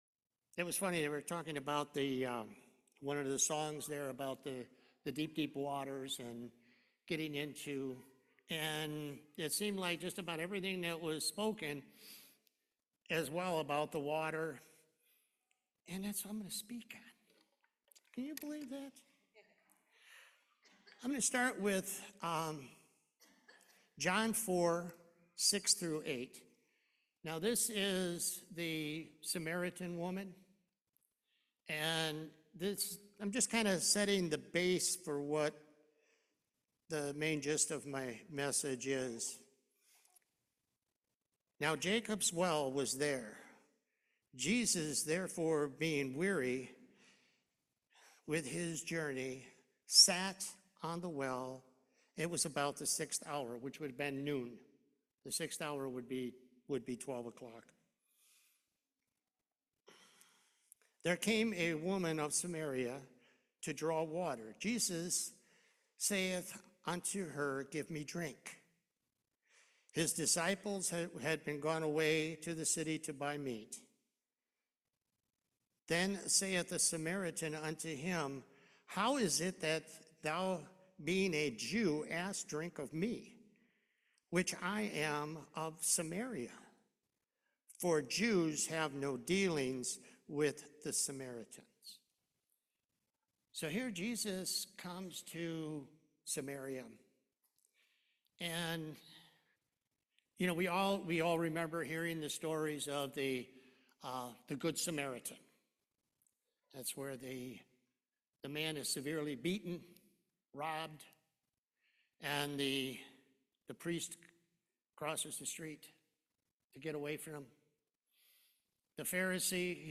Acts 2:1 Service Type: Main Service He is the living water.